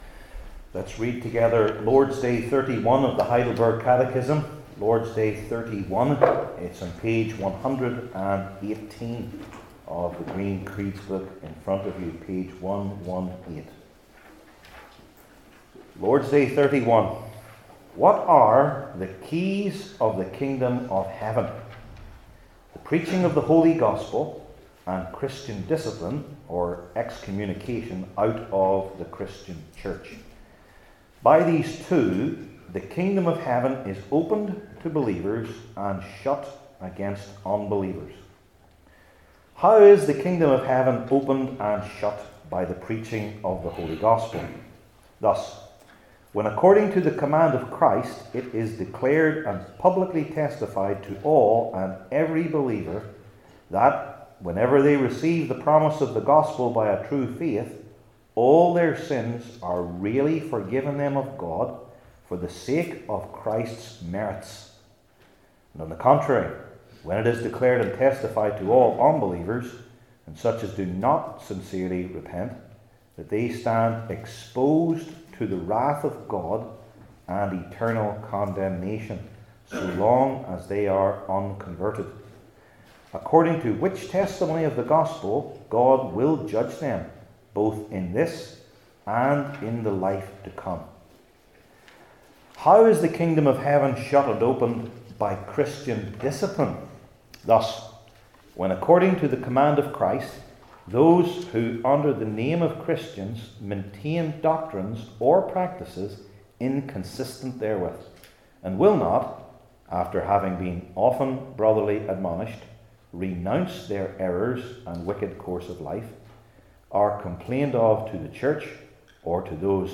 Heidelberg Catechism Sermons